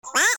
What(Highpitch)